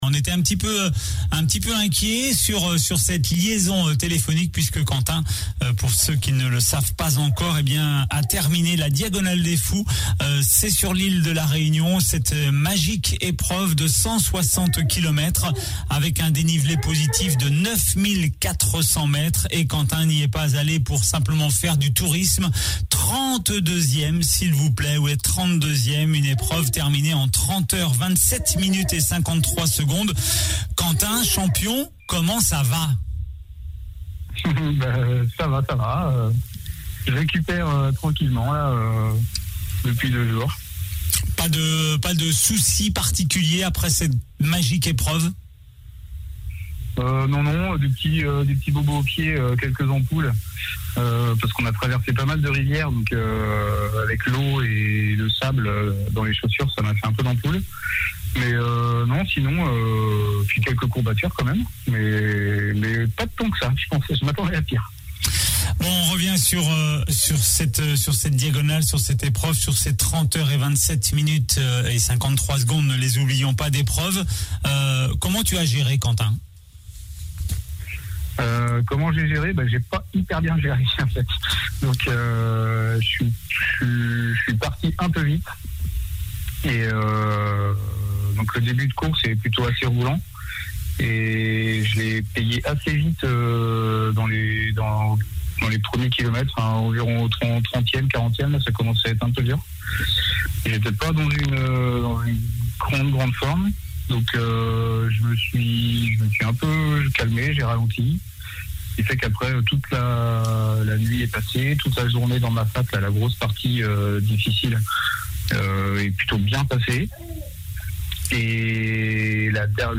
Entretien complet